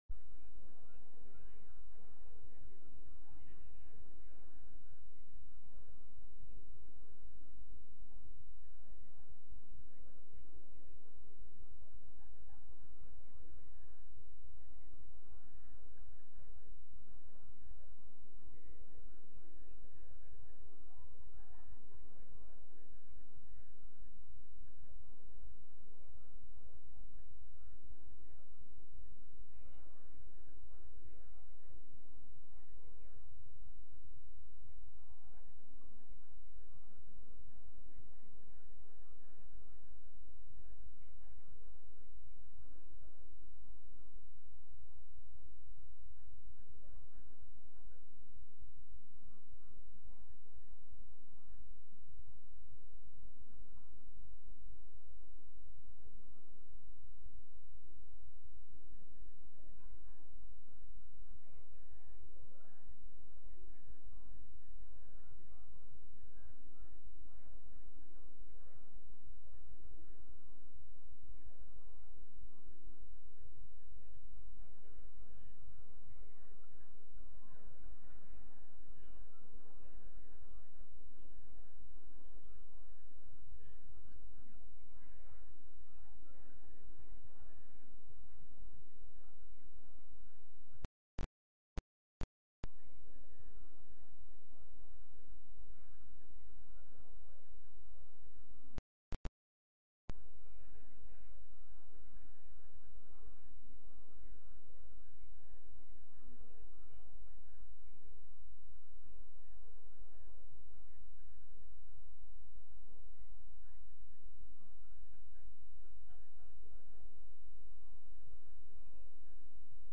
From Series: "Sunday Worship"
Sunday-Service-6-5-22.mp3